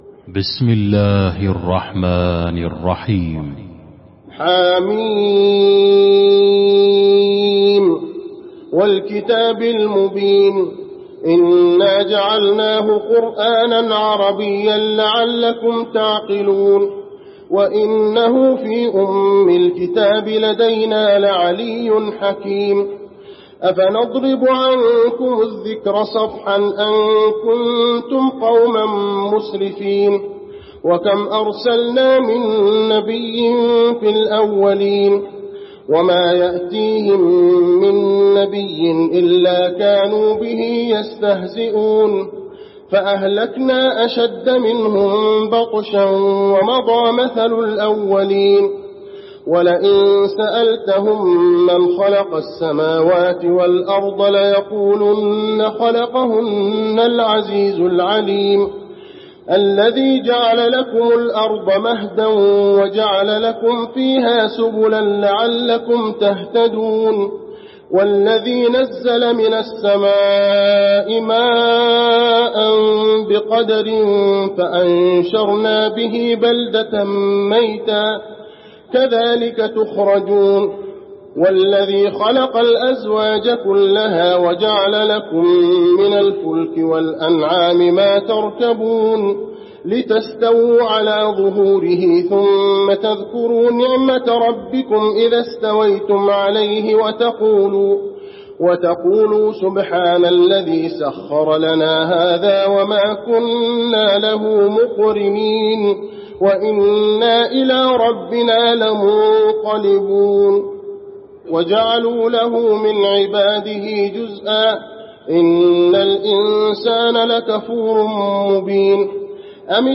المكان: المسجد النبوي الزخرف